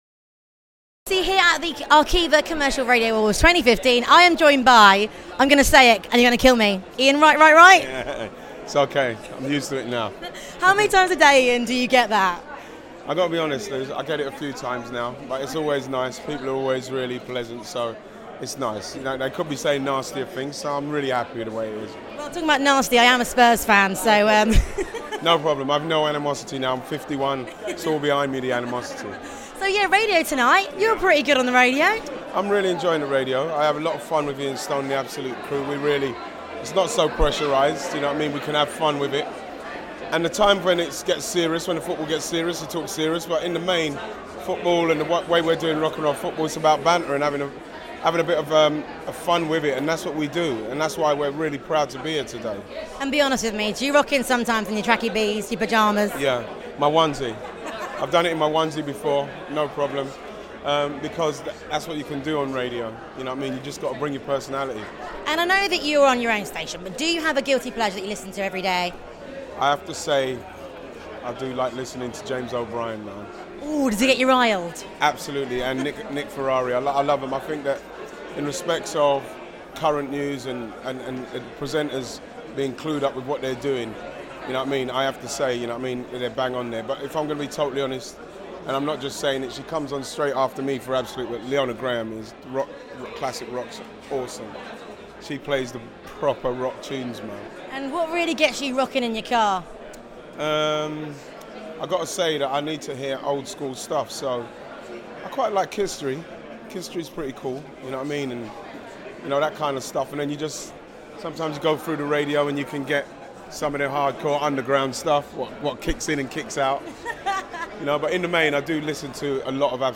RadioToday Live Interviews / Ian Wright is here at the Arqivas!